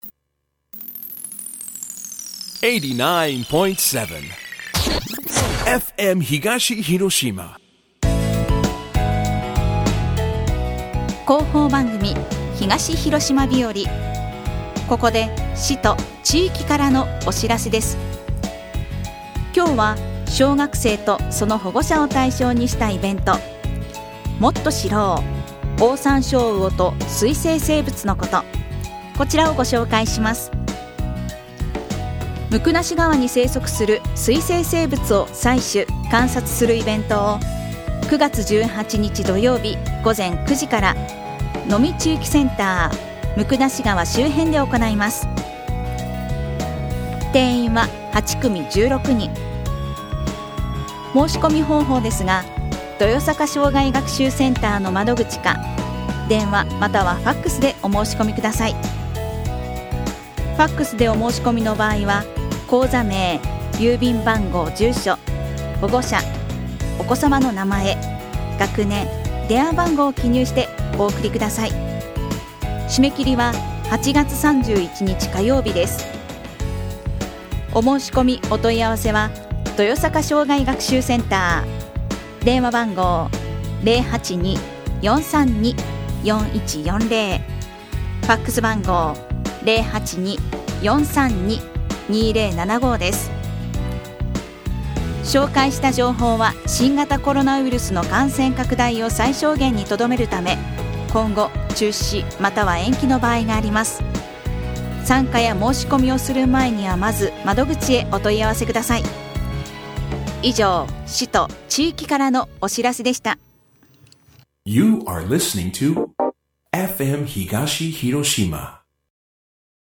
2021年8月21日にＦＭ東広島で朝放送した広報番組「東広島日和」です。今日は「もっと知ろう！オオサンショウオと水生生物のこと」についてです。